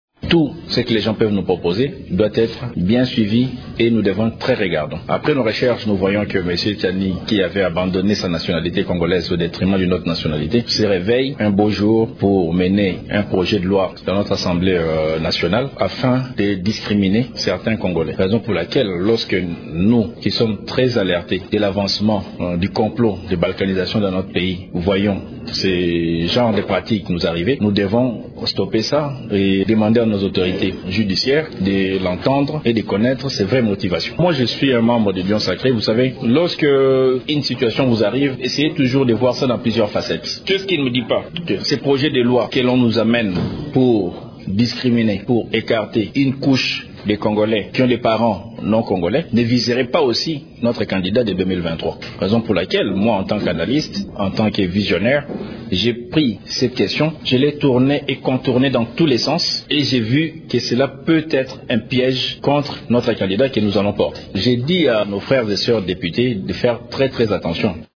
Au cours d'une conférence de presse organisée ce week end à Kinshasa, il estime que l’on doit définitivement mettre fin à ces velléités ségrégationnistes de l’auteur de cette proposition de loi.
« Je dis à nos frères et sueurs députés de faire très attention », a-t-il insisté dans cet extrait sonore :